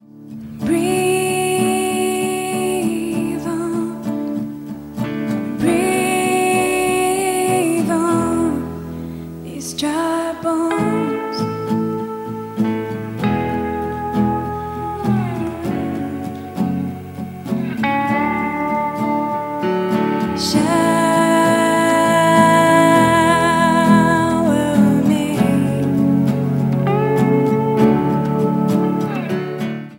live worship
was recorded live during the worship at the annual